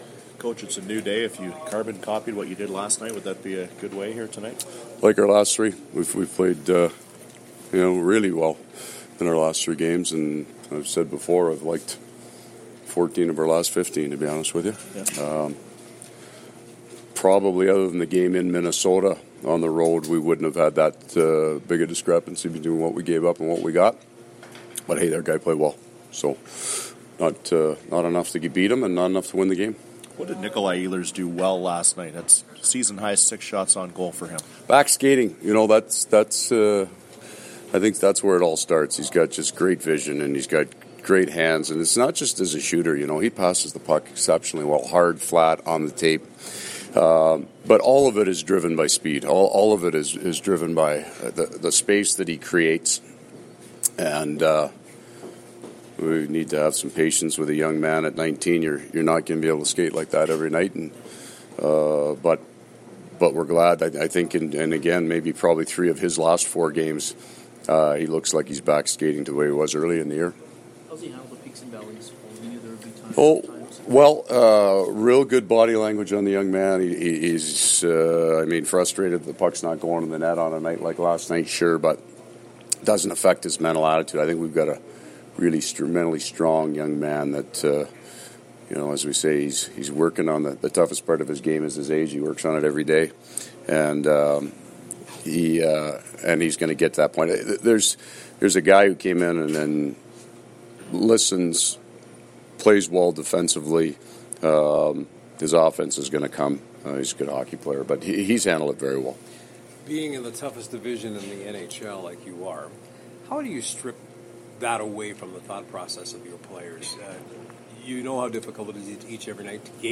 Coach pre-game scrum
Coach Maurice’s game day comments.